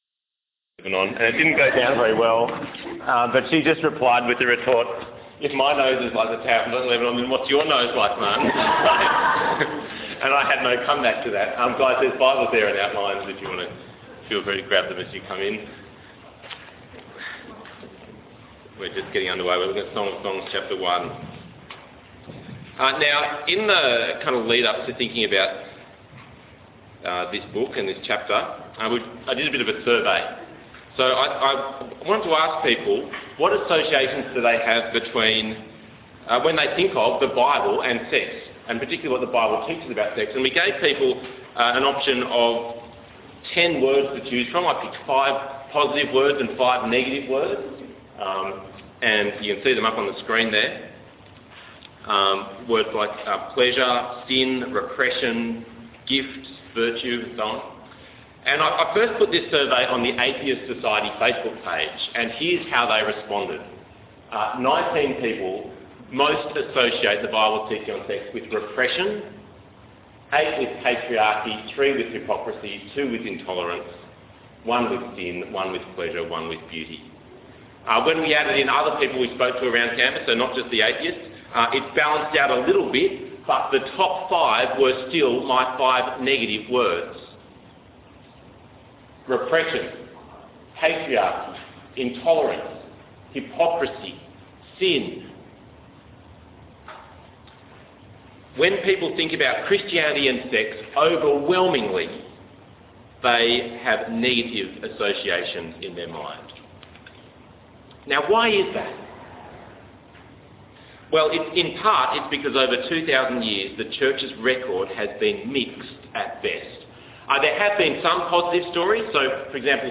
The Finest Love Song Passage: Song of Solomon 1:1-2:17 Talk Type: Bible Talk